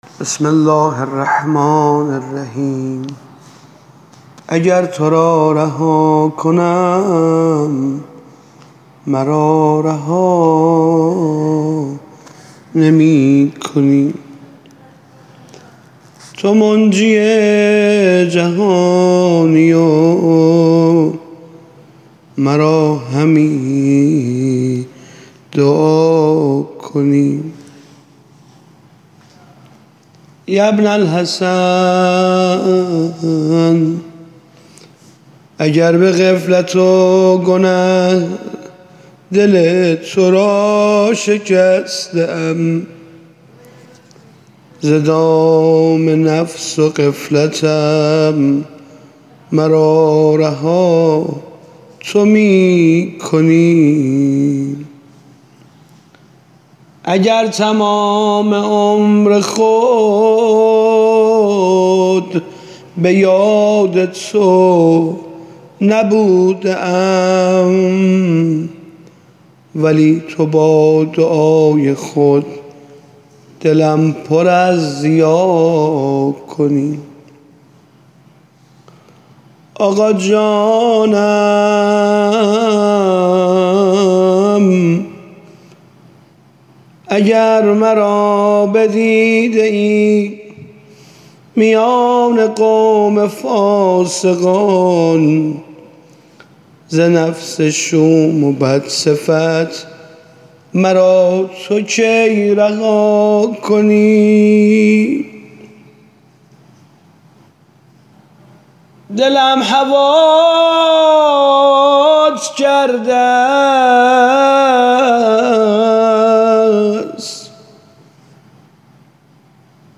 دانلود تواشیح جدید برای امام زمان (عج)